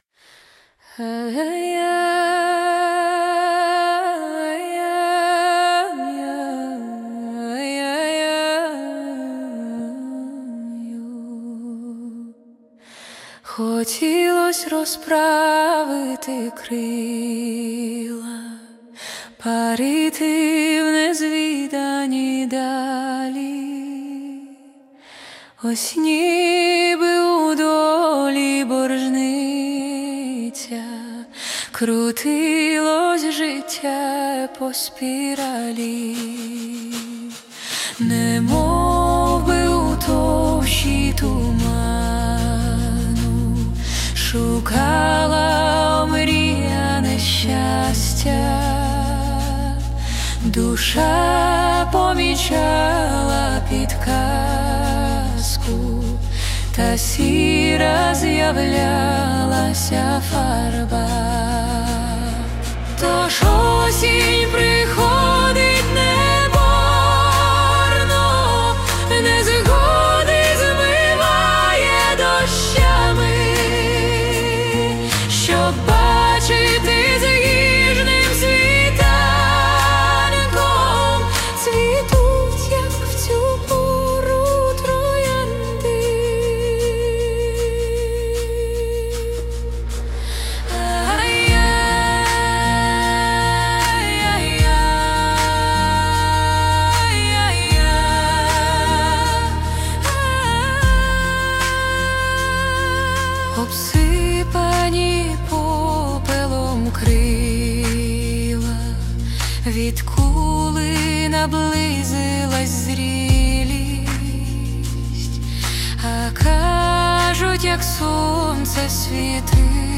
Музична композиція створена за допомогою SUNO AI
Дуже гарна пісня, хоча й сумна, можливо тільки я, саме так, сприйняла її.